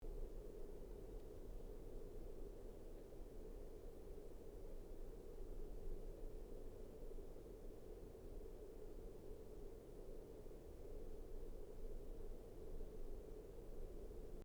Возникли шумы при записи через карту Duet for iPad & Mac
При записи на конденсаторные микрофоны (с фантомом) пару дней назад внезапно появились шумы.
Шум по всему спектру, незначительный, пропустить сложно, для записи не допустимый, ранее не встречался.
С динамическим миком становится более высокочаcтотным. Шум улицы исключен.
Файл с записью шума прилагается Загадка.